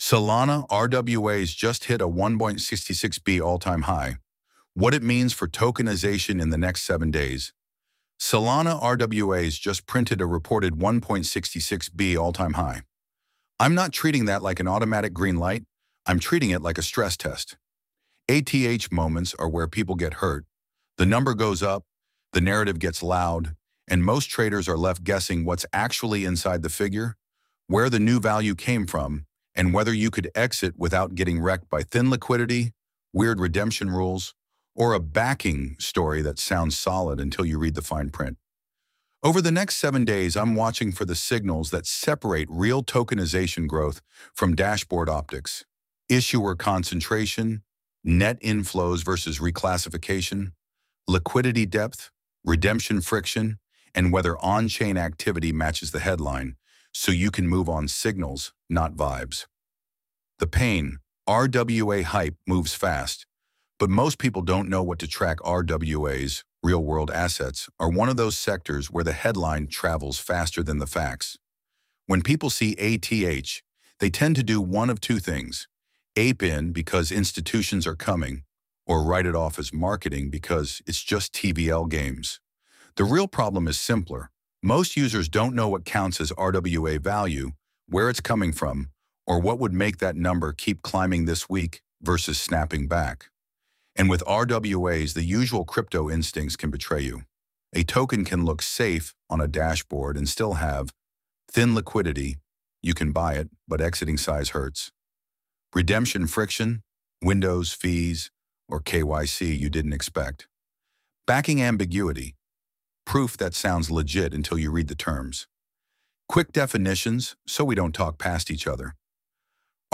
Solana-RWAs-Just-Hit-a-1.66B-All‑Time-High-—-audio-redable-article.mp3